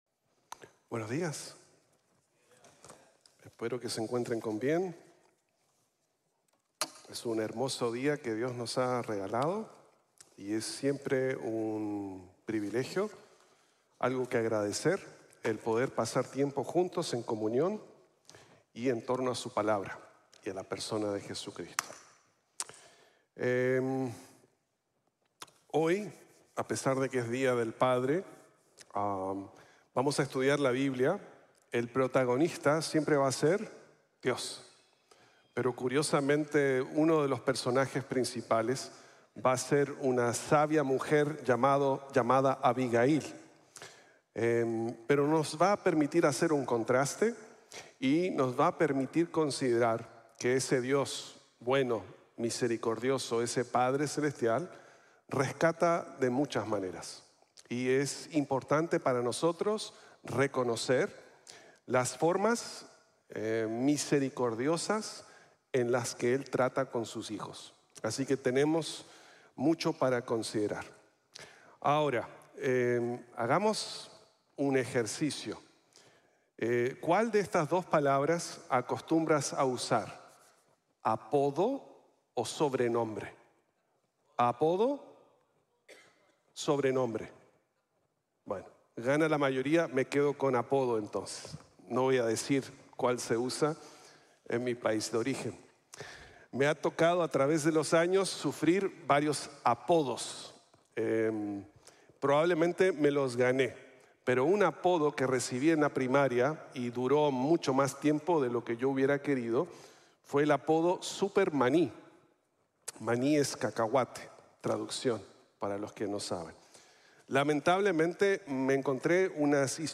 El Dios Bendito Impide | Sermón | Iglesia Bíblica de la Gracia